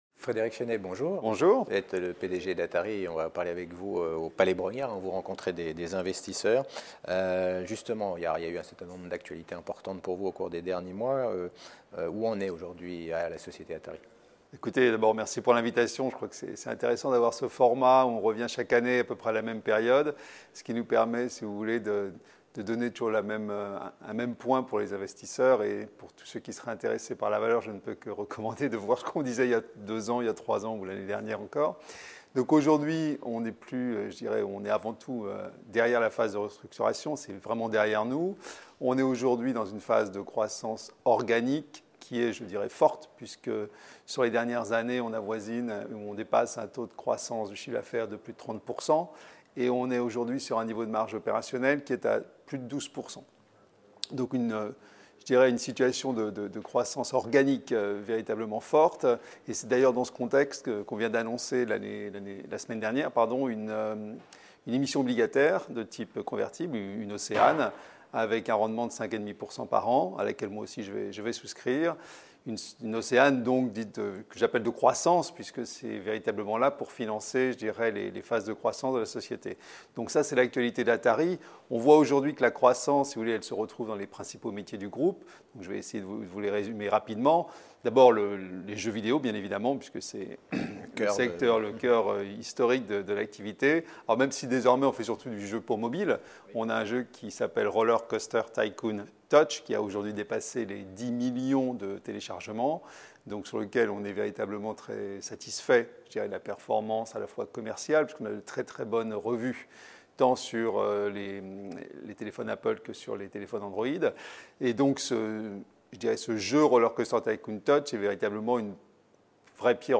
Lors de l’European Large & Midcap Event 2017 organisé par CF&B Communication à Paris, la Web TV partenaire a rencontré de nombreux dirigeants.